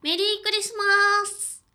「喜」のタグ一覧
ボイス
女性